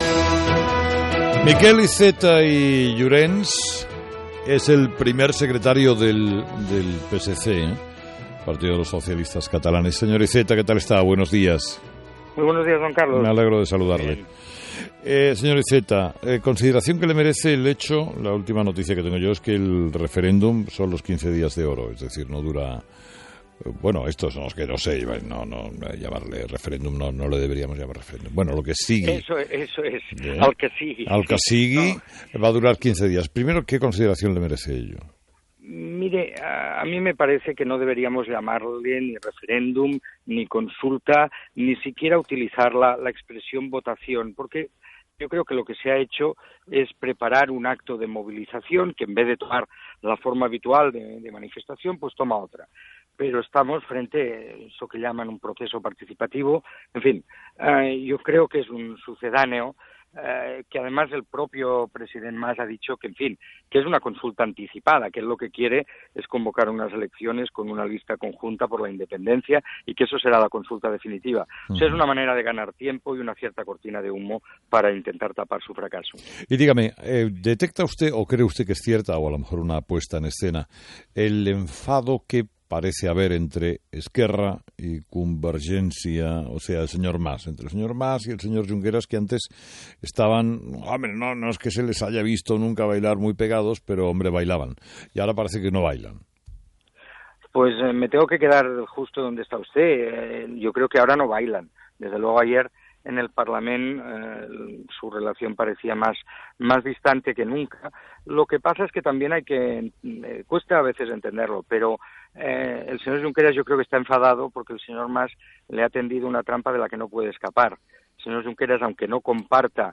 Entrevista a Miquel Iceta